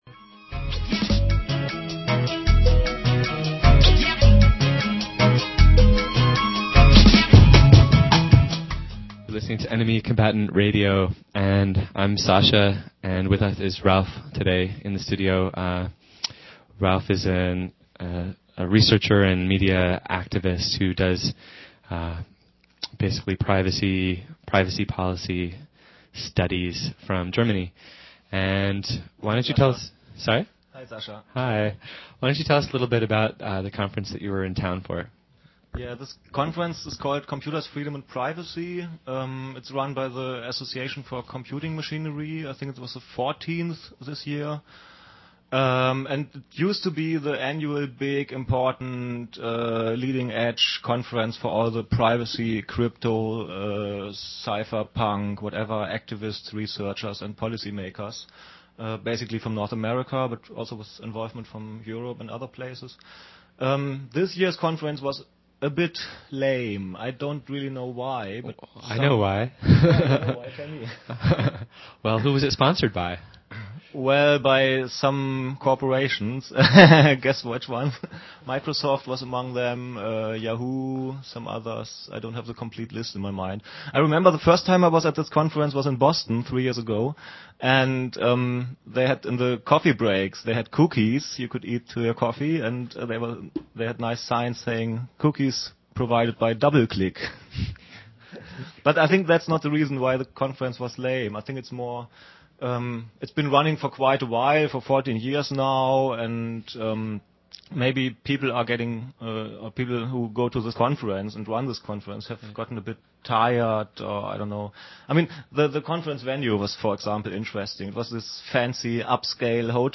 interviews media activist